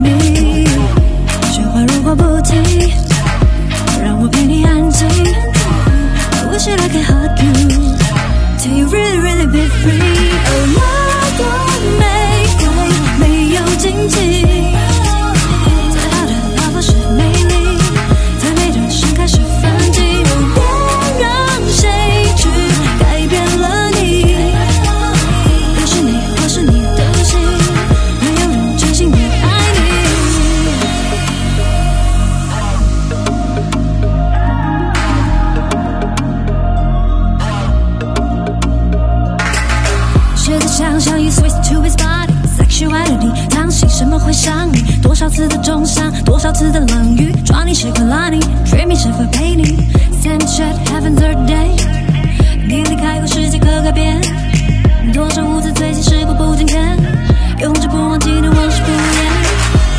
mellifluous song